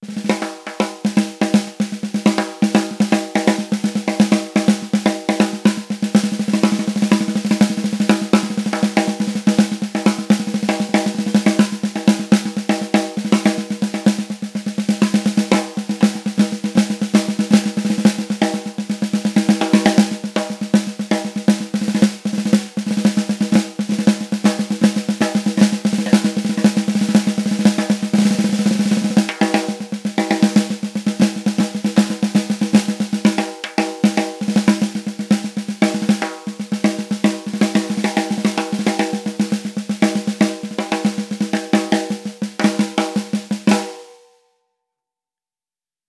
Lagadec Percussions Caisse Claire 14″x5,5″ Ash
Fûts 3 plis horizontaux en frêne (100% francais)
Cerclages chromés 3mm
Peaux Aquarian
Timbre artisanal Bibin (24 brins en acier)